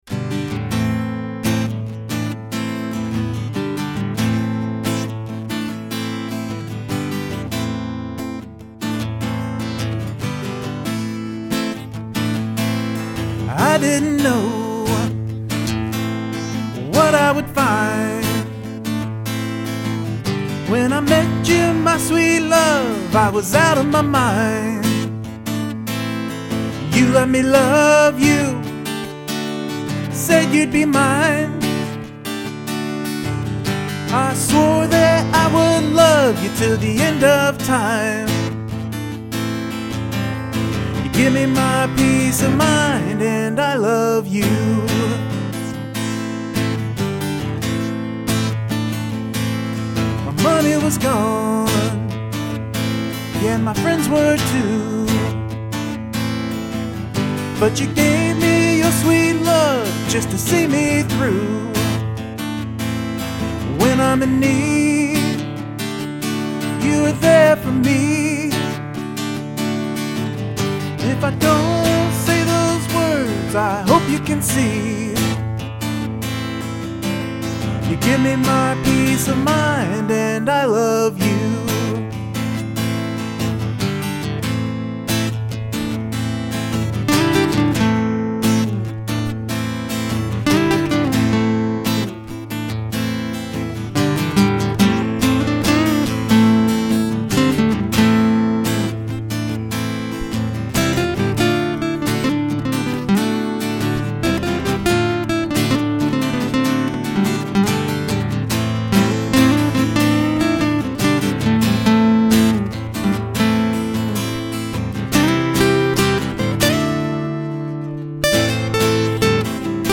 I like the feel of this one.
And the lead parts were great!
Good vocals!!
Voice and lead guitar are superb.
Solo is very unique but fits very well.
The recording is very crisp and clear which helps the song come across really well.